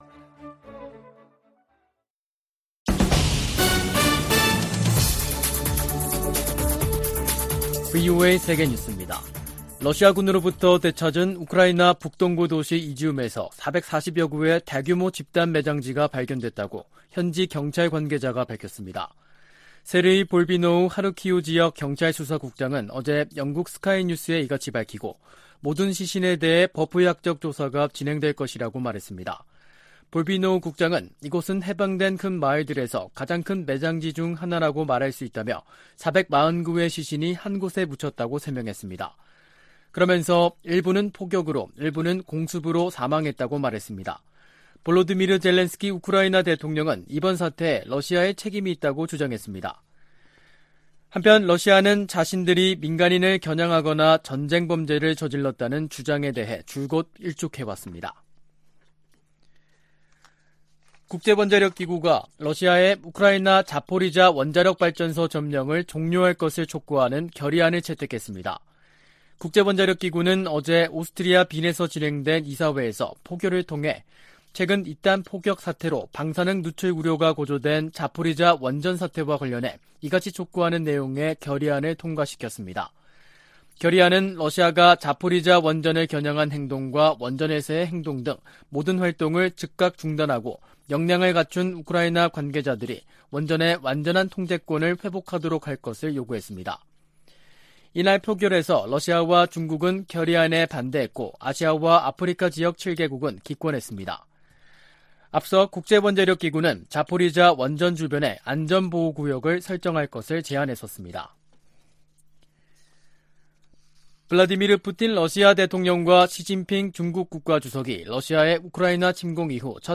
VOA 한국어 간판 뉴스 프로그램 '뉴스 투데이', 2022년 9월 16일 3부 방송입니다. 리잔수 중국 전국인민대표대회 상무위원장은 북핵 문제에 관해 한반도 평화체제 구축이 당사국들의 이익에 부합한다는 원칙을 거듭 밝혔습니다. 미 국무부는 미한 확장억제전략협의체 회의에서 북한 위협에 맞서 협력을 확대하는 방안이 논의될 것이라고 밝혔습니다. 미국 전략사령관 지명자가 북한 핵 미사일 위협에 맞선 차세대 요격 미사일(NGI) 개발을 지지한다고 말했습니다.